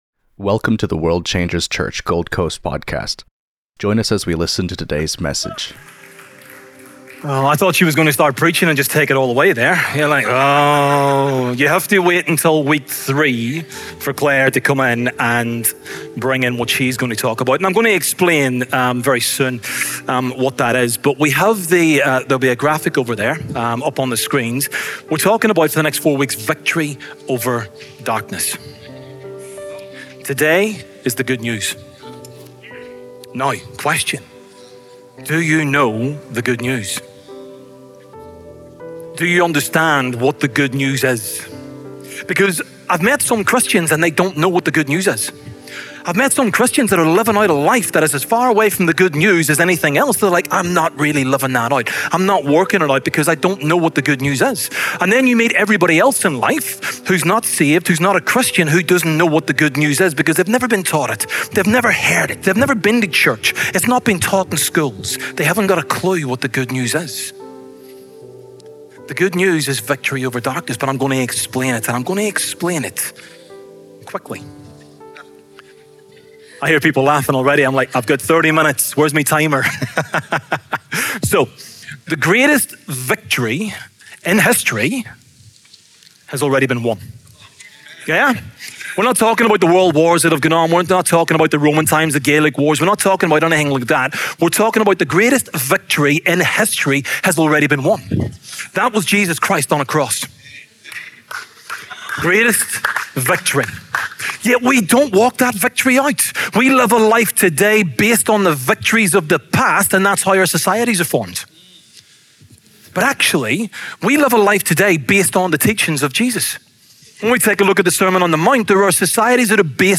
It highlights the finished work of the cross, emphasizing that sin, shame, and condemnation have been defeated. Encouraging believers to walk in the authority given through Christ, the sermon challenges perspectives on fear, doubt, and identity.